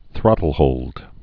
(thrŏtl-hōld)